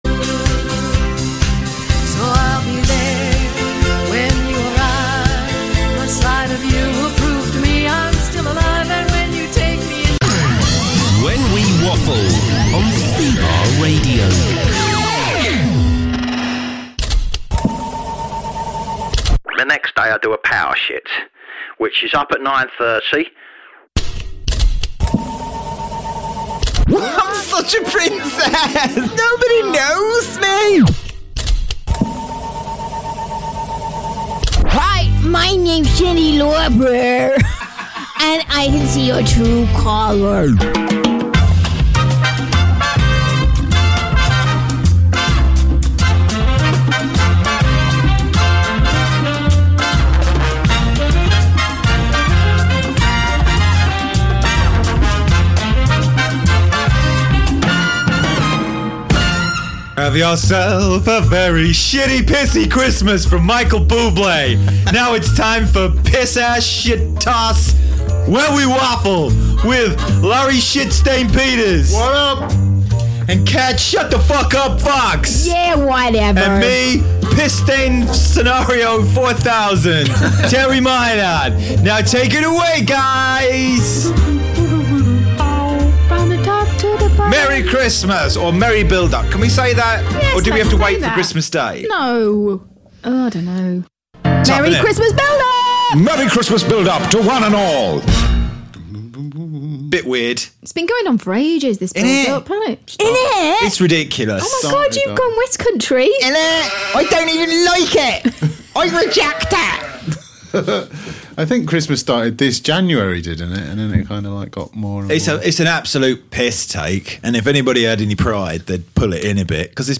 The Mimic Terry Mynott mocks beloved and less beloved stars whilst bantering with his co-hosts